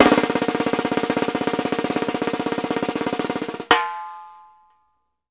FFROLL +AC-L.wav